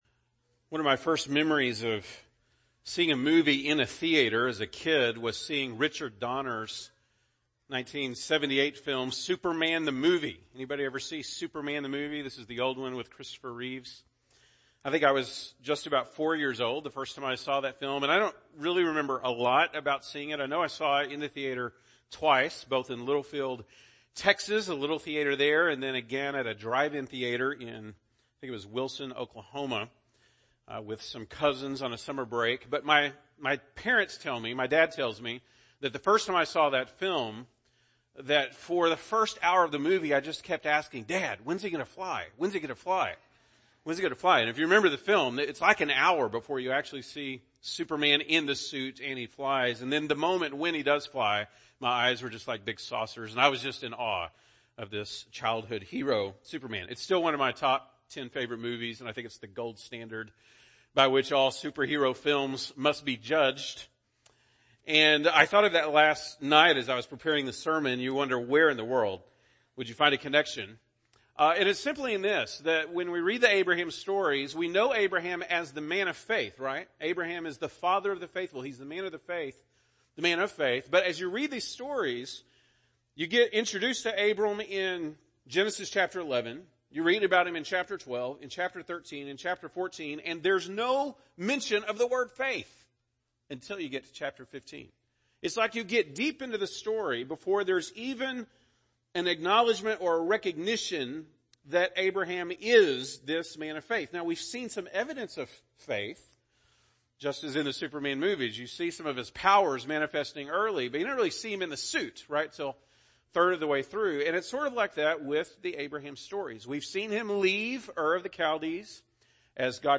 June 16, 2019 ( Sunday Morning )